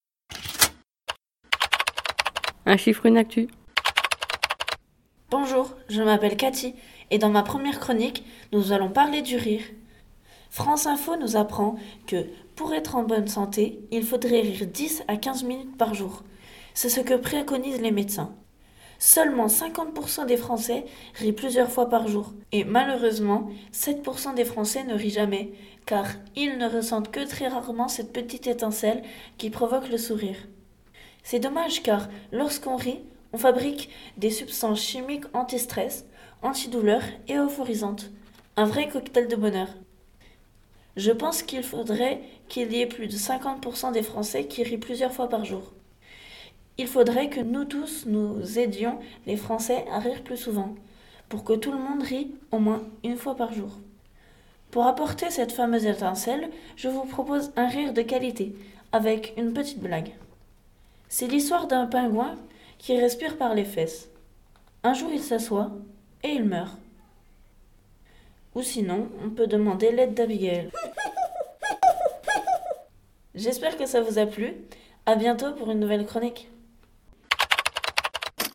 rire.mp3